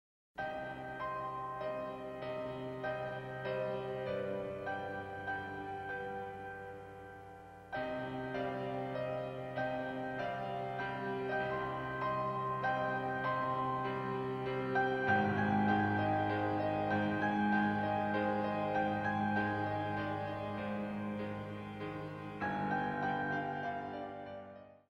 46 Piano Selections.